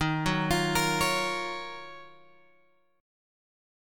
D# Minor 9th